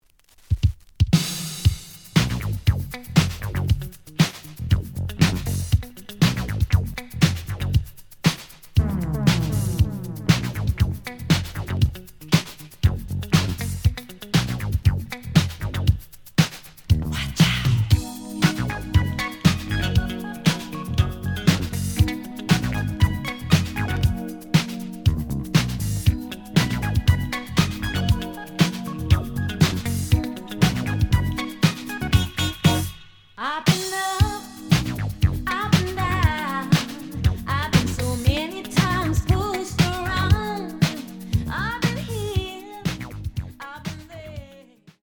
The audio sample is recorded from the actual item.
●Genre: Disco
Slight noise on beginning of A side, but almost good.)